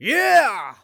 人声采集素材